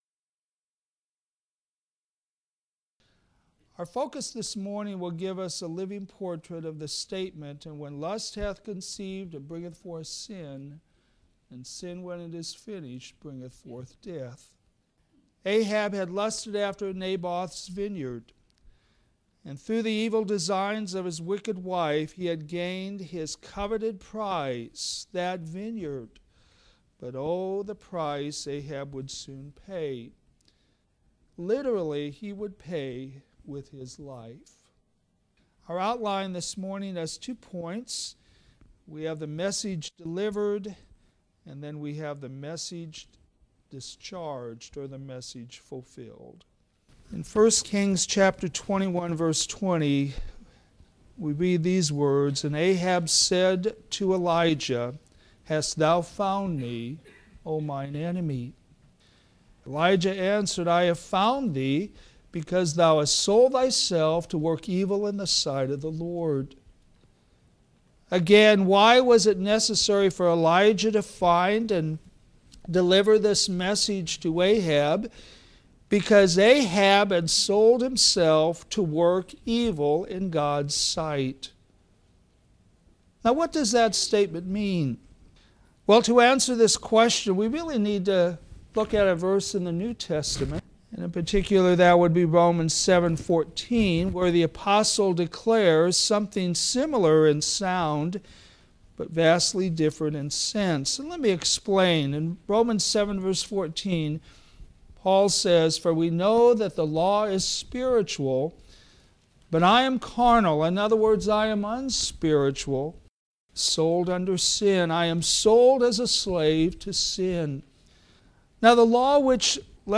All sermons available in mp3 format
Sunday AM